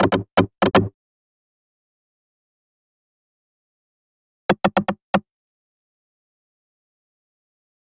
Session 08 - Tribal Percussion.wav